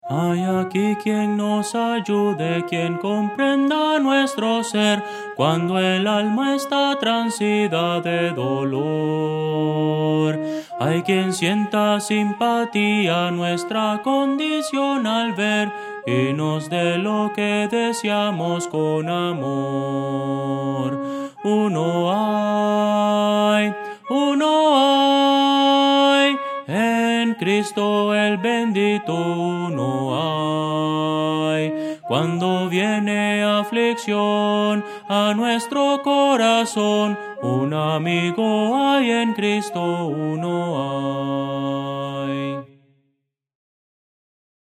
Voces para coro
Audio: MIDI